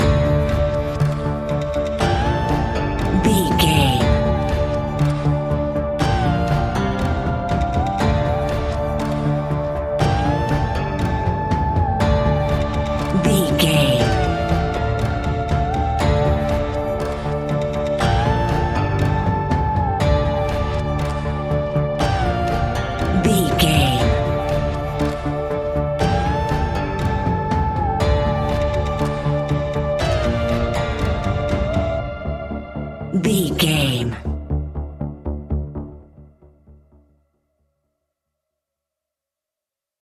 Aeolian/Minor
D
ominous
dark
eerie
electric guitar
drums
synthesiser
horror music